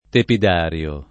tepid#rLo] s. m.; pl. -ri (raro, alla lat., -rii) — meno com. tepidarium [lat. tepid#rLum], pure s. m. in it. (tanto «stanza tiepida delle antiche terme» quanto «serra non riscaldata»); pl. -ria — non tiepidarium — cfr. caldarium